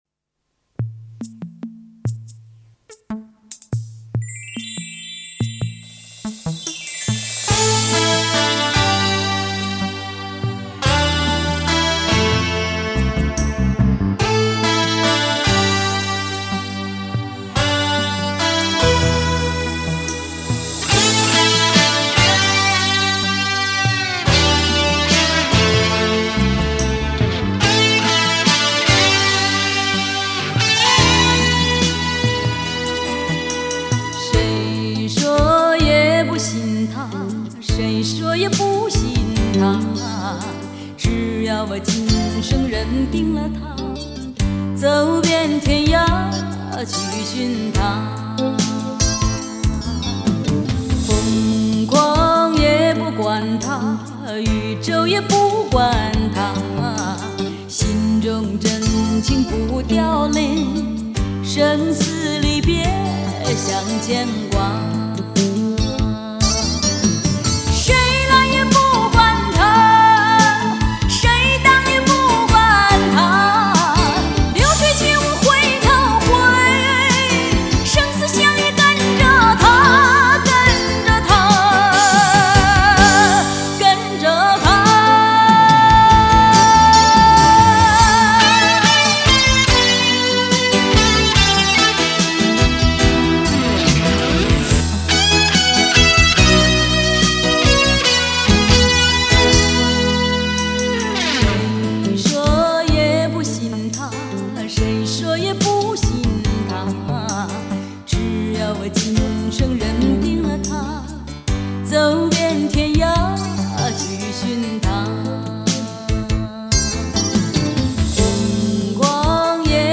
片尾曲